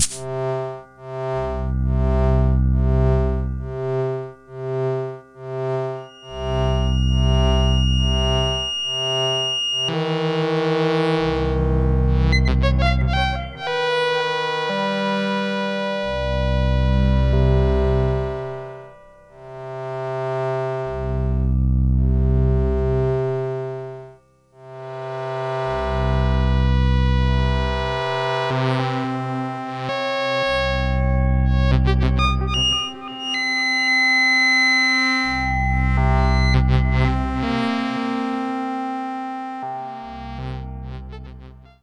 低音总是播放低音。
合成器创建具有不同特征的定义音轨。
Tag: 模拟 EP 电子 生成 合成器 EURORACK 模块化 机的组合物 合成器 噪声